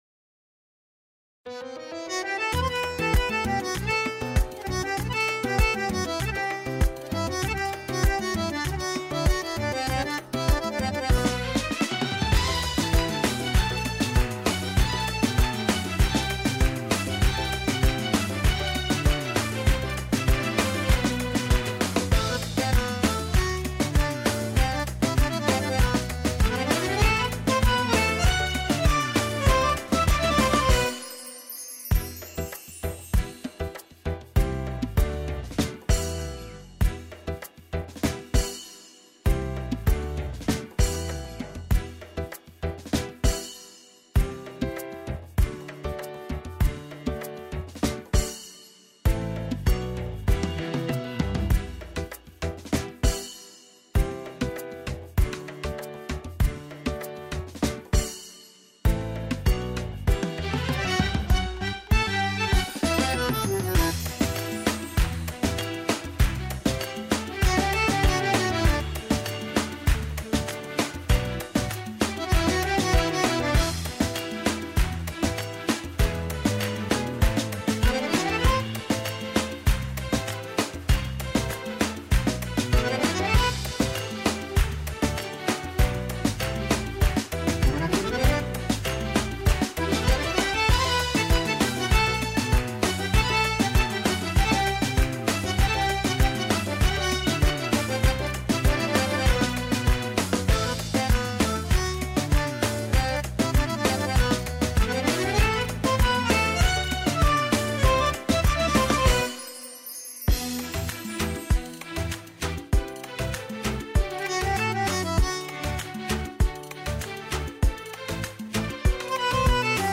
دانلود سرود زیبای